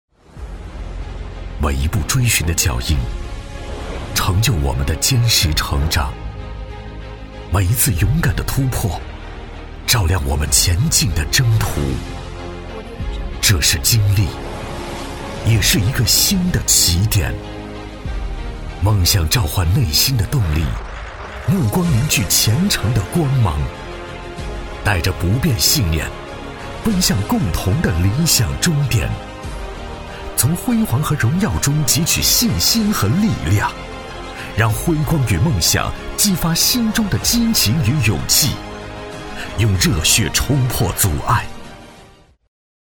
男42-颁奖【开场片（激励感）】
男42-磁性质感 大气浑厚
男42-颁奖【开场片（激励感）】.mp3